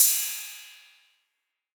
808CY_4_Orig_ST.wav